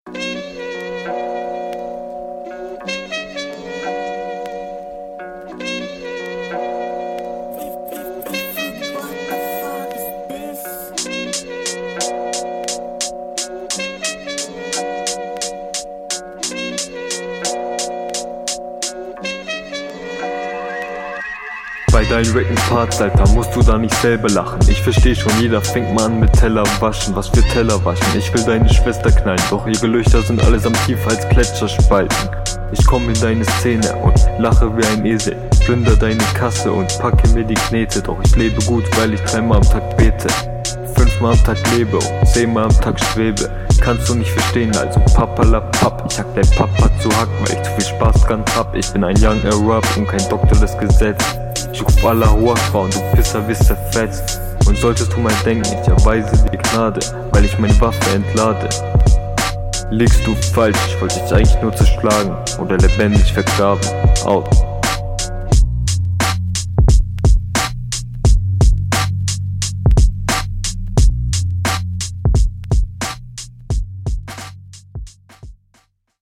Flow: Du bist auf dem Takt und verständlicher als in der RR.